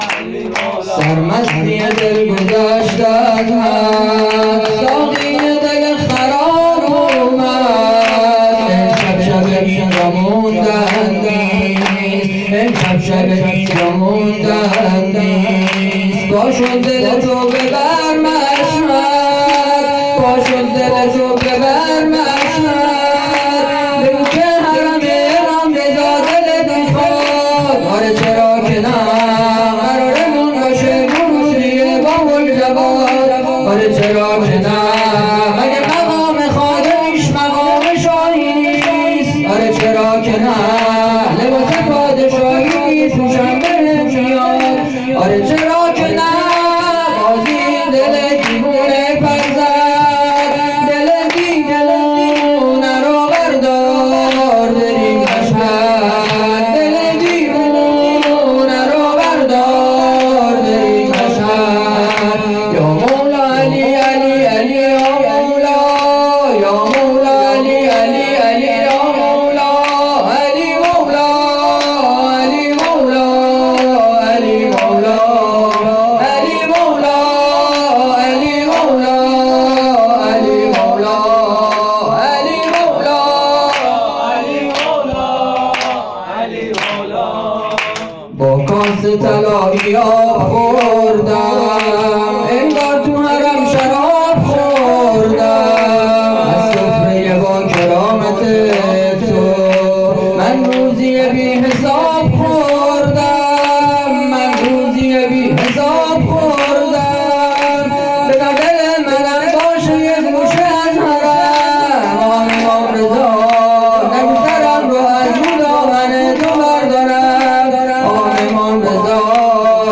ولادت امام رضا(ع)